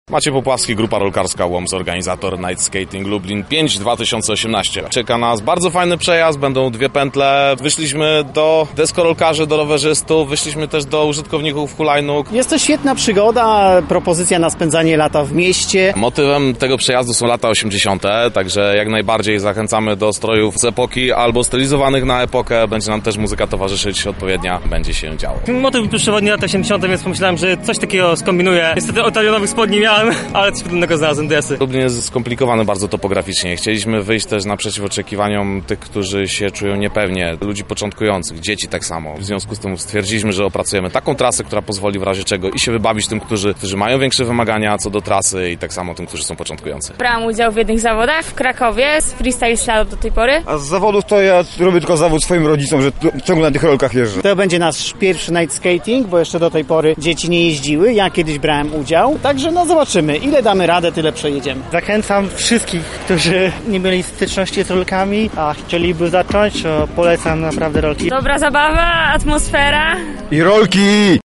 Także na rolkach, uczestnikom towarzyszył nasz reporter: